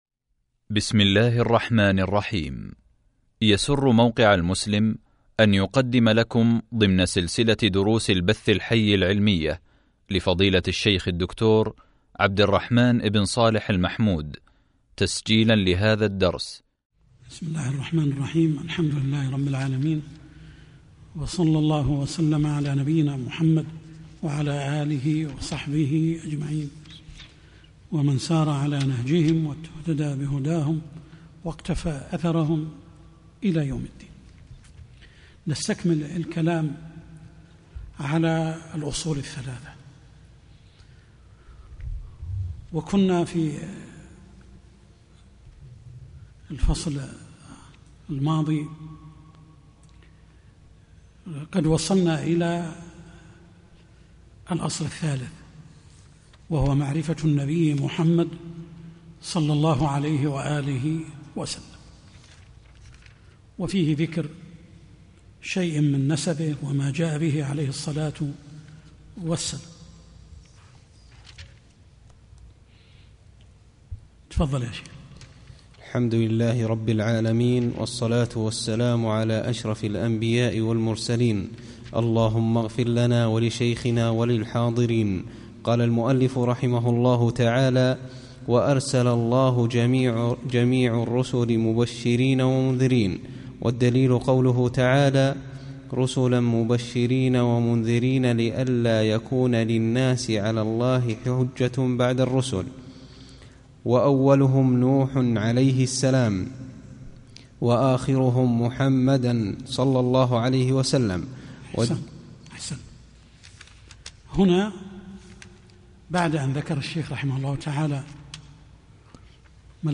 شرح الأصول الثلاثة | الدرس 22 | موقع المسلم